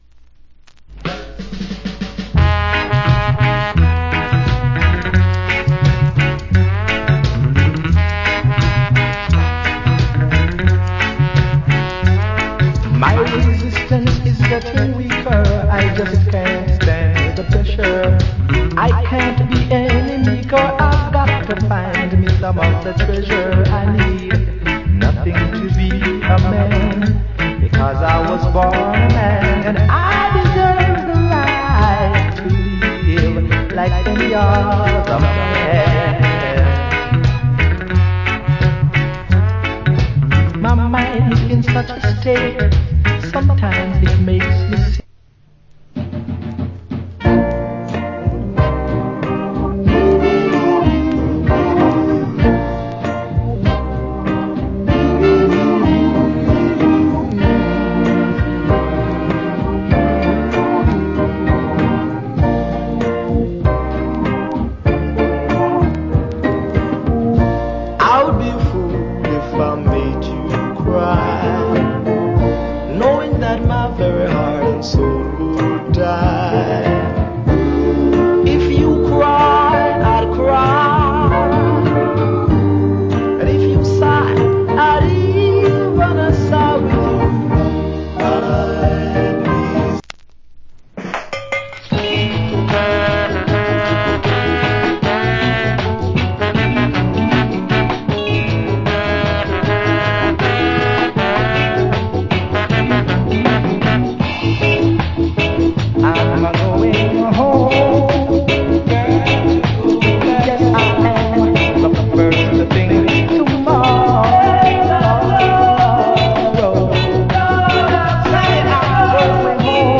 Great Rock Steady Vocal. 80's JA Press.